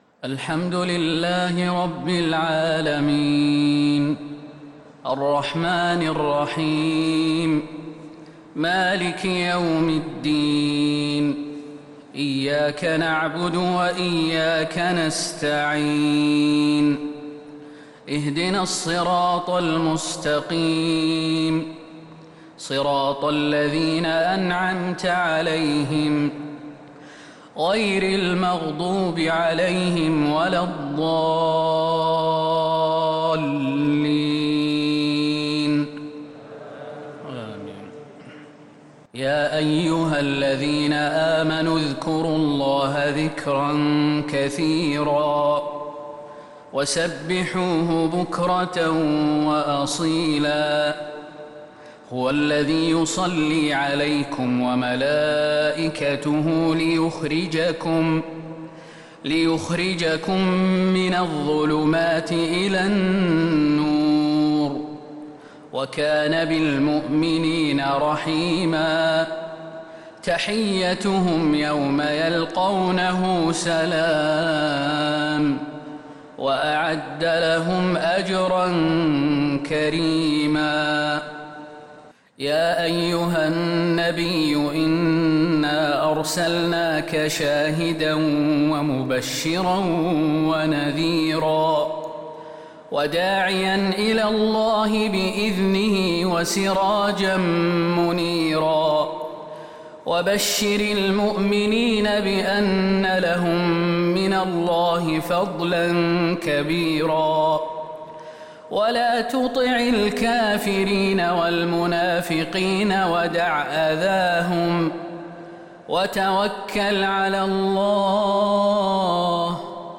صلاة المغرب للقارئ خالد المهنا 15 شوال 1442 هـ
تِلَاوَات الْحَرَمَيْن .